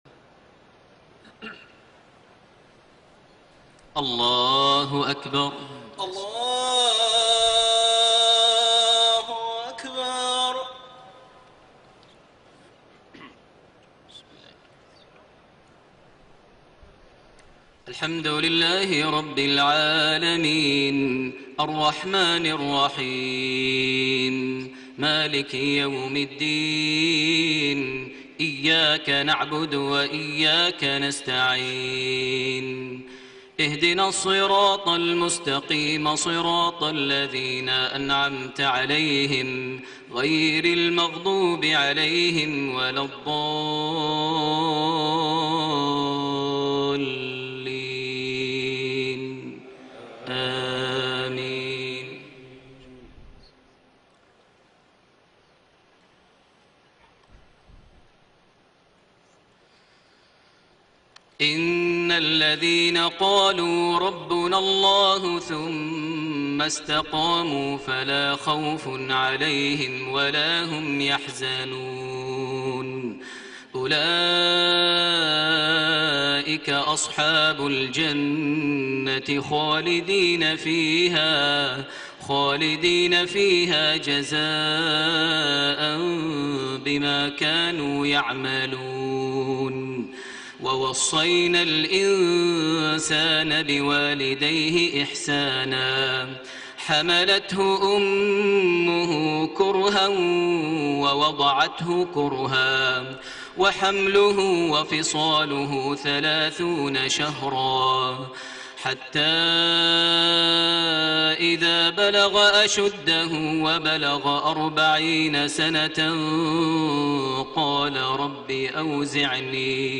صلاة المغرب 19 رجب 1433هـ من سورة الاحقاف 13-19 > 1433 هـ > الفروض - تلاوات ماهر المعيقلي